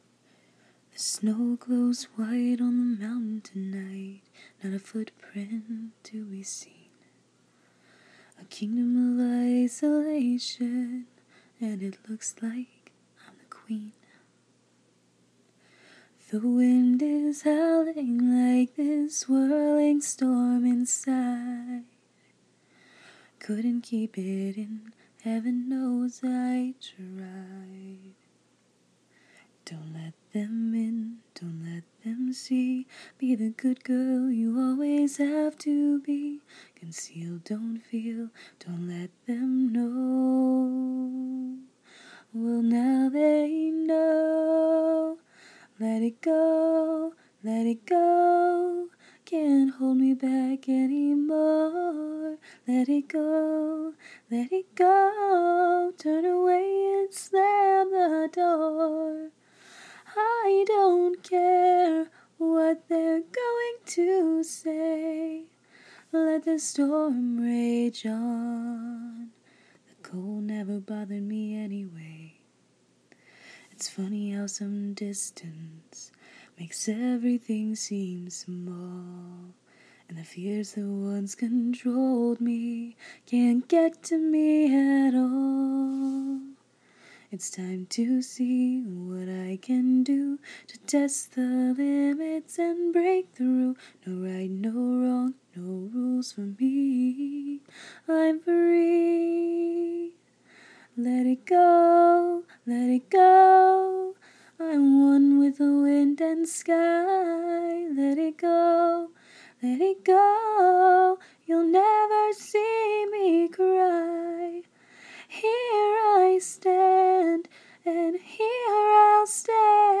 Squeakyraspypitchyvoice.